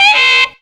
OVERBLOW 3.wav